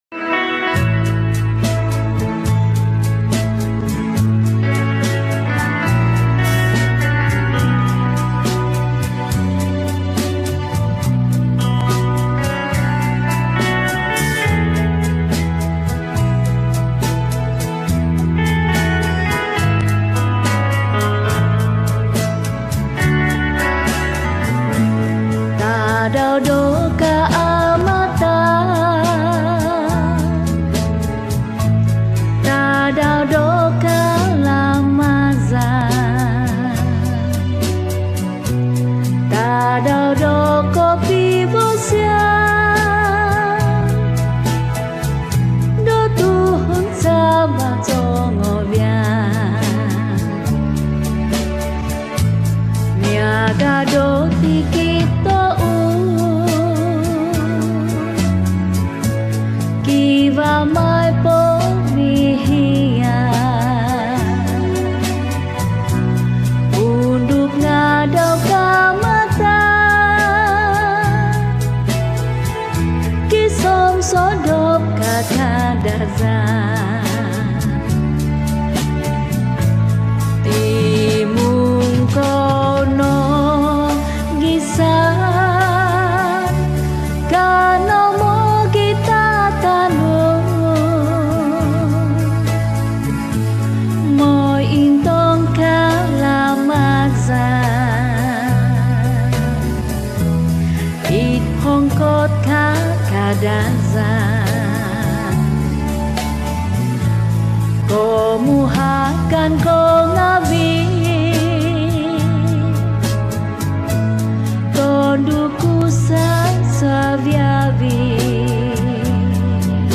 Kadazan Song
Skor Angklung